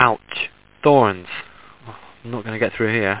home *** CD-ROM | disk | FTP | other *** search / Horror Sensation / HORROR.iso / sounds / iff / ouchthor.snd ( .mp3 ) < prev next > Amiga 8-bit Sampled Voice | 1992-09-02 | 29KB | 1 channel | 9,016 sample rate | 3 seconds
ouchthor.mp3